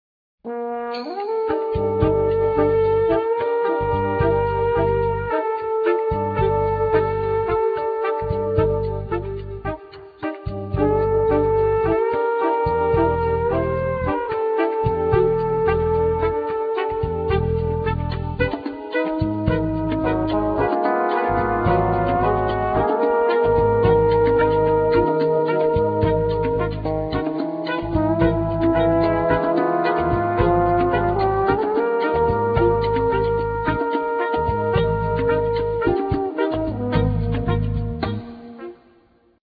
Drums
Trombone
Percussion
Tenor Sax,Clarinet
Bass
Trumpet